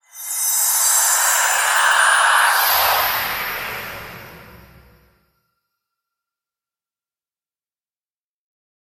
Звук произношения заклинания женским голосом